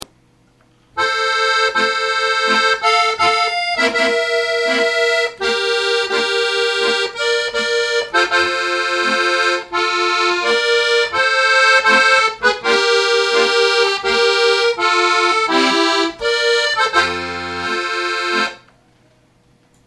Professional Full Size Accordion, Musette, New
HERE (MM) to listen to the beautiful sounds